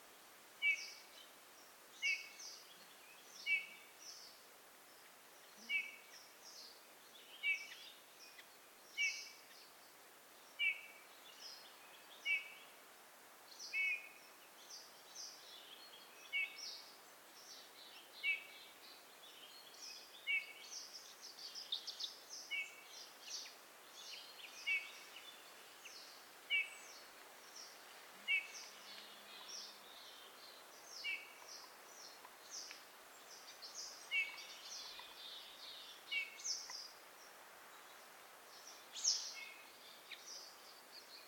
Вы можете слушать и загружать их пение в разных вариациях: от нежных переливов до звонких трелей.
Звучание снегиря в дикой природе